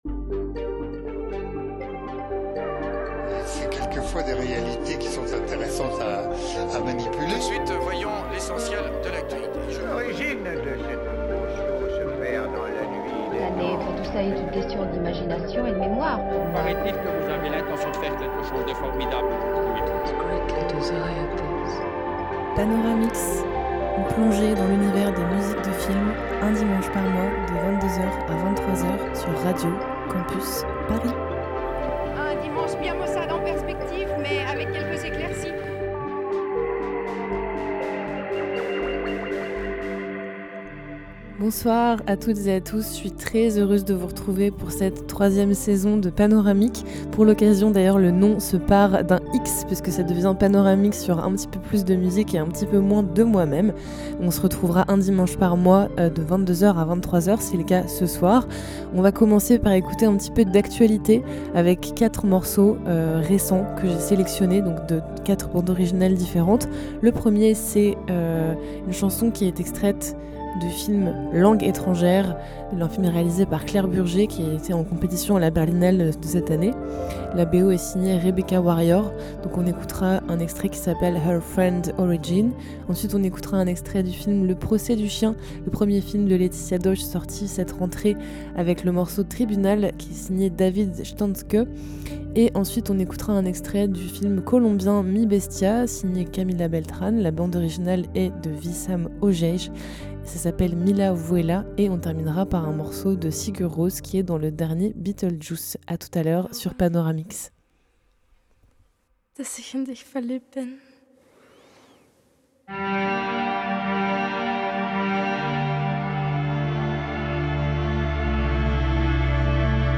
Type Mix